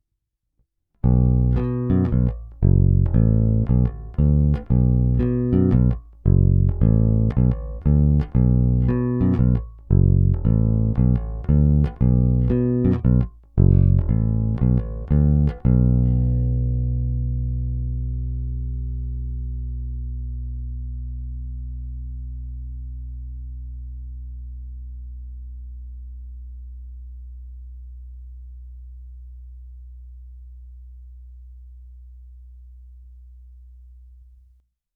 Není-li uvedeno jinak, nahrávky jsou provedeny rovnou do zvukové karty, bez stažené tónové clony a bez použití korekcí.
Hráno nad použitým snímačem, v případě obou hráno mezi nimi.
Snímač u krku